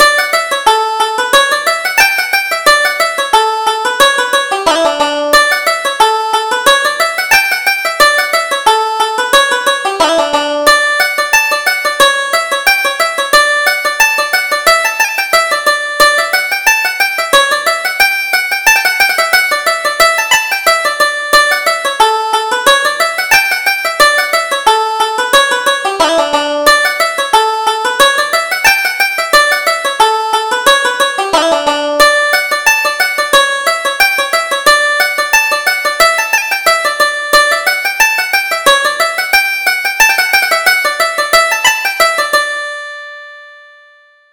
Reel: The Sailor's Jacket